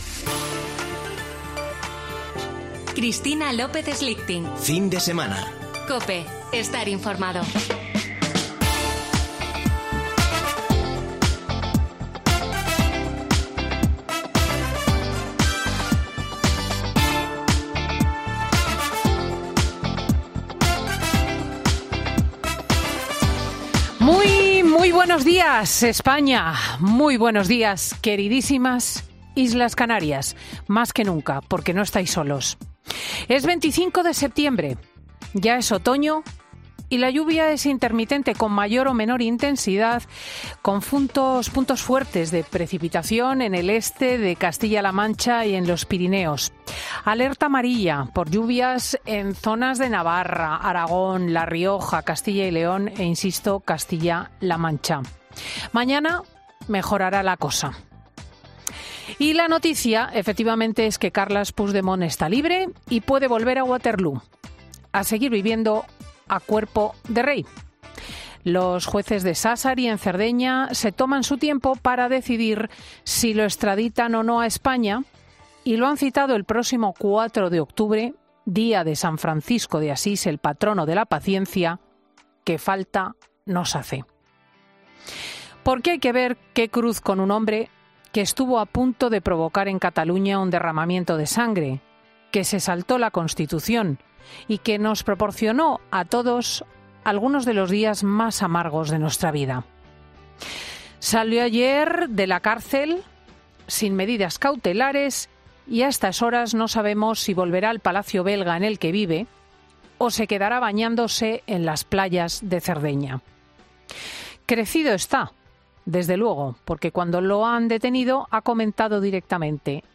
La presentadora de 'Fin de Semana' reflexiona sobre la detención de Puigdemont y la situación que siguen atravesando la isla de La Palma